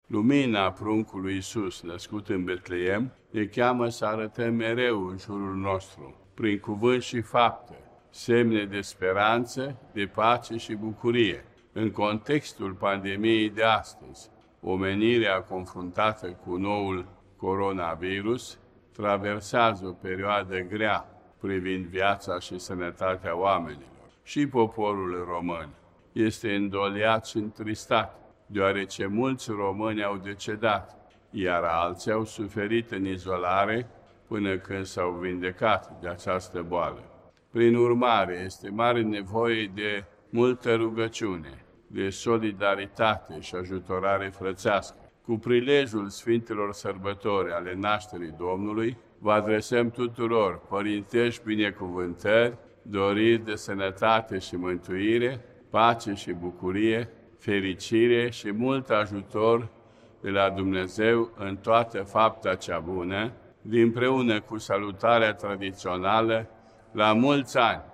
În această perioadă de pandemie, trebuie să dăm dovadă de solidaritate şi să-i sprijinim pe cei aflaţi în nevoie, ne îndeamnă Patriarhul Daniel al Bisericii Ortodoxe Române în mesajul său de Crăciun.